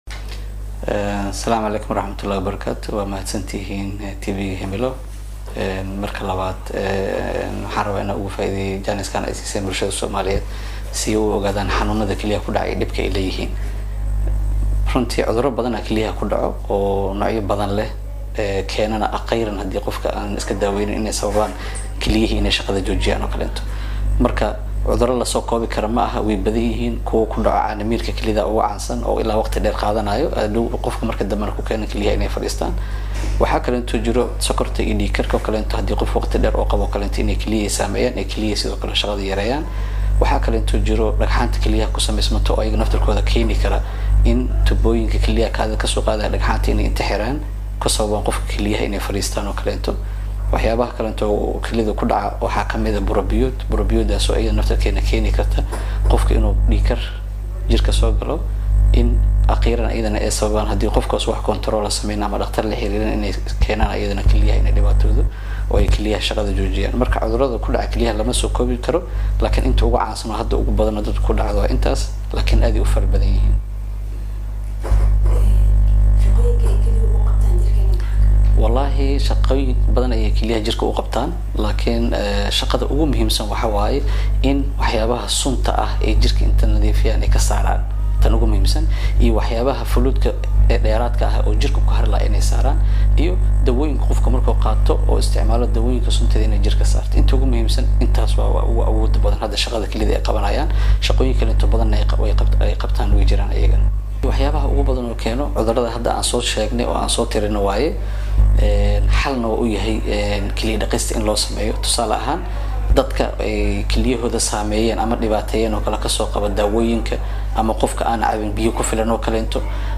Maxay yihiin cuddurada ku dhacaya kelyaha?,Wareysi